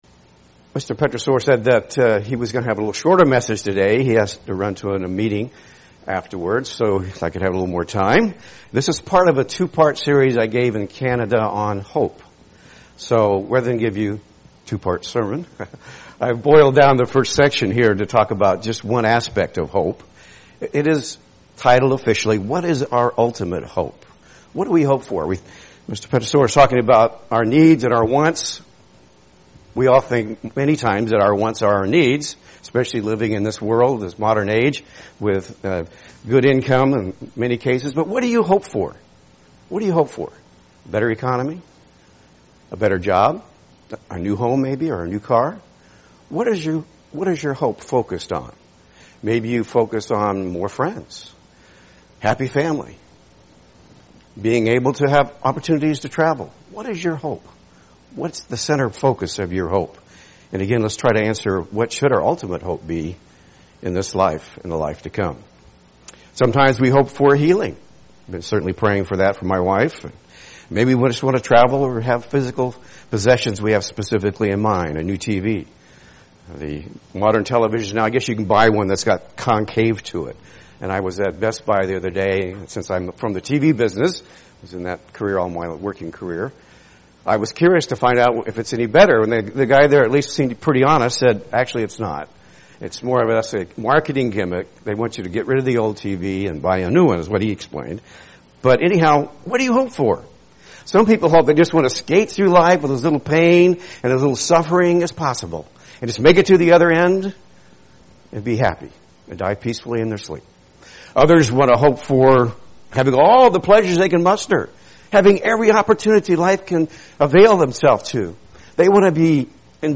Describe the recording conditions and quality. Given in Seattle, WA